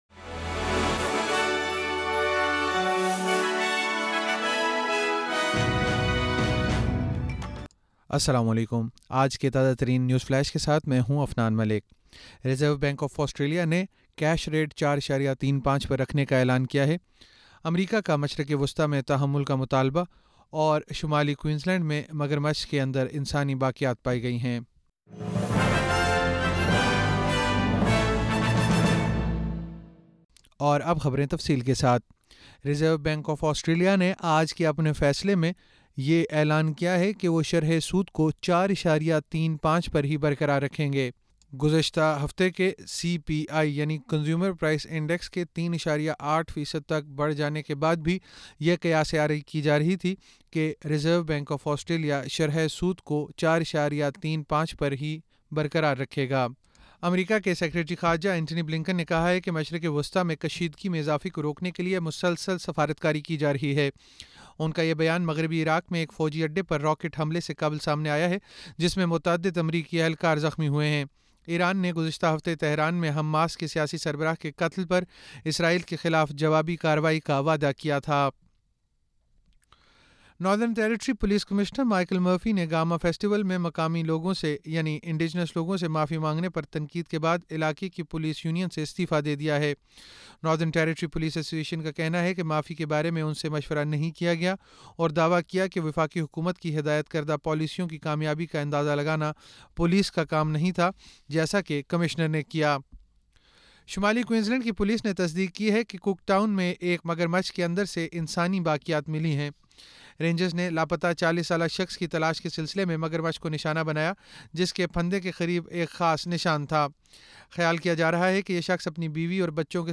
نیوز فلیش 06 اگست 2024: ریزرو بنک آسٹریلیا کا شرح سود 4.35 فیصد پر برقرار رکھنے کا فیصلہ